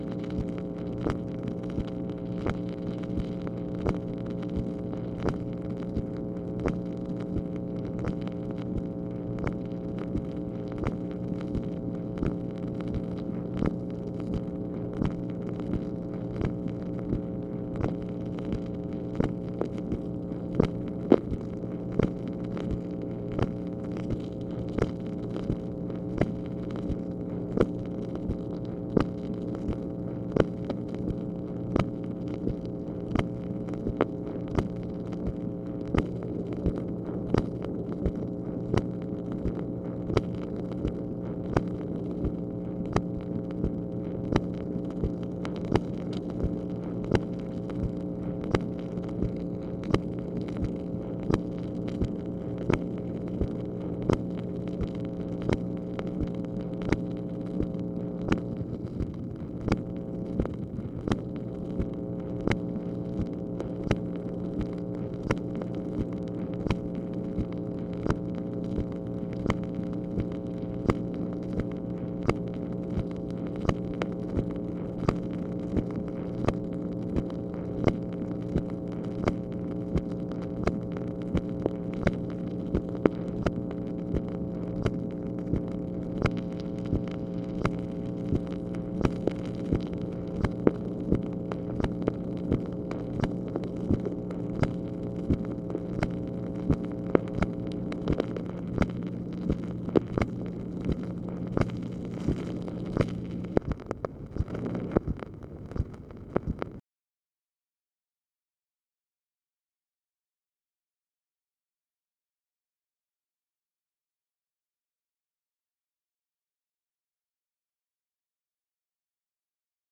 MACHINE NOISE, February 16, 1965
Secret White House Tapes | Lyndon B. Johnson Presidency